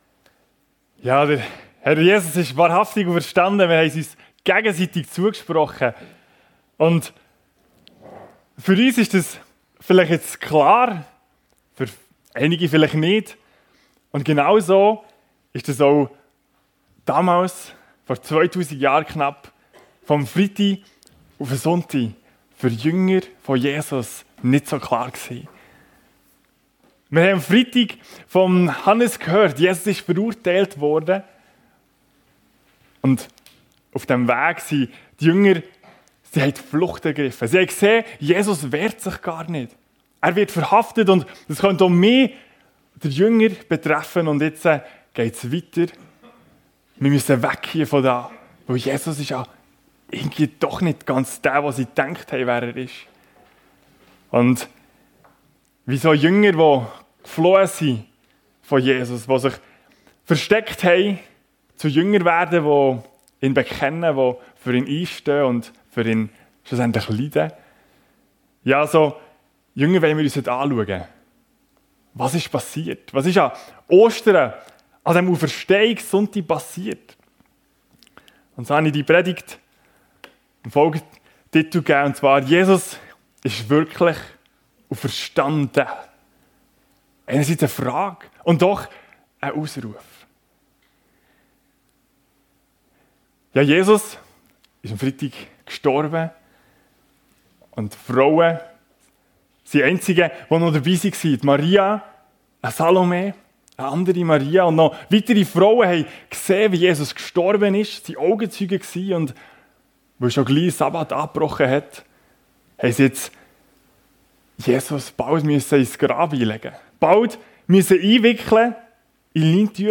Jesus ist wirklich auferstanden?! ~ FEG Sumiswald - Predigten Podcast